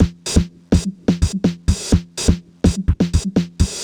cch_jack_percussion_loop_regrade_125.wav